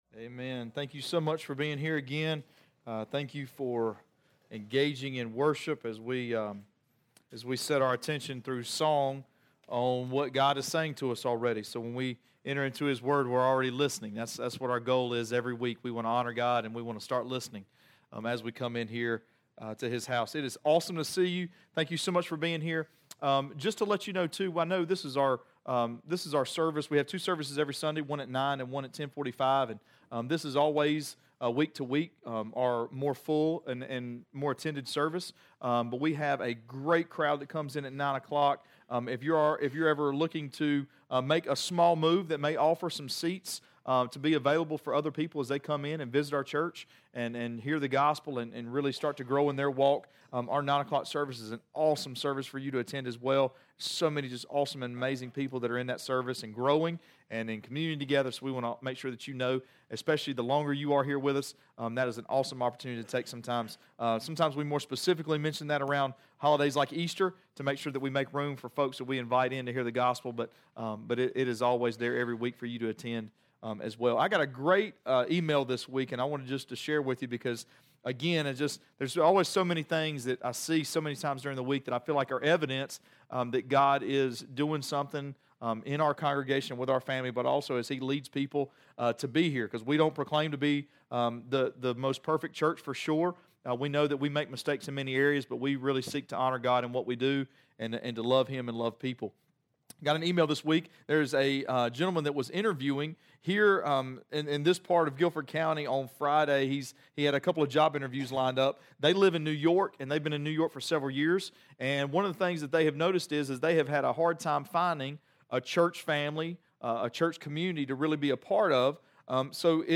Sermons Archive - Page 39 of 60 - REEDY FORK COMMUNITY CHURCH